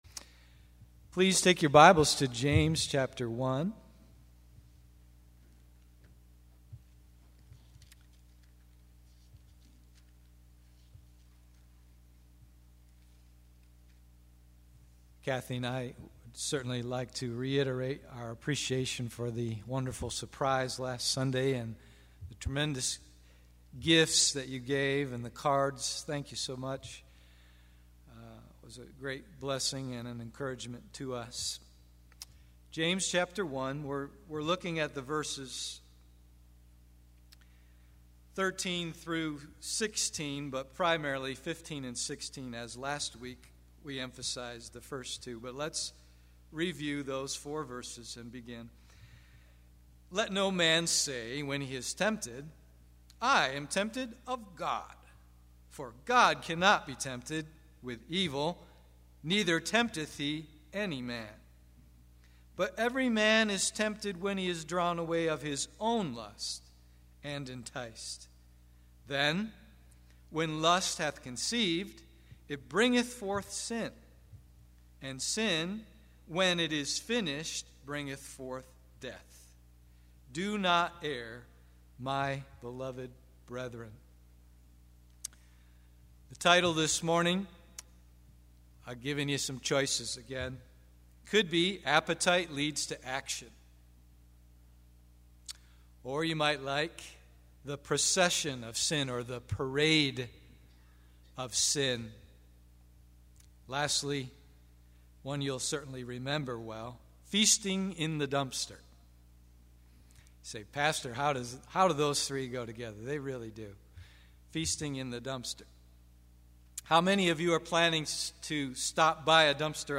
“God is a Giver” (part 1) Sunday AM